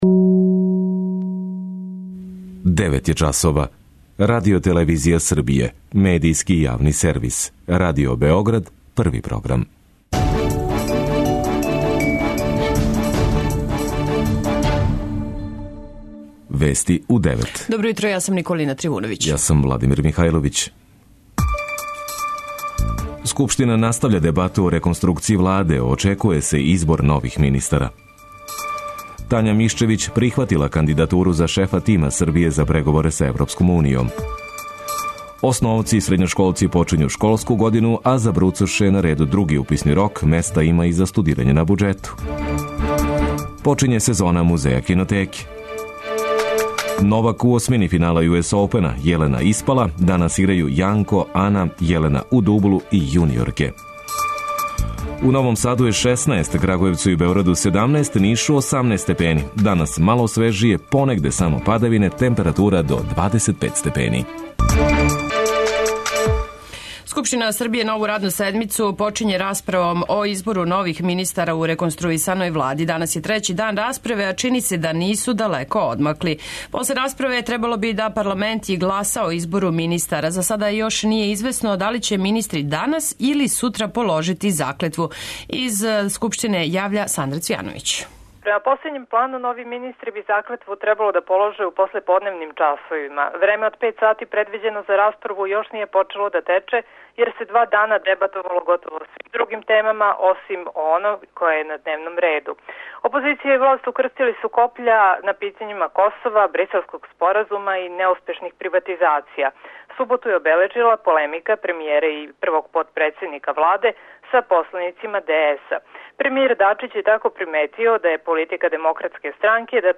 преузми : 10.68 MB Вести у 9 Autor: разни аутори Преглед најважнијиx информација из земље из света.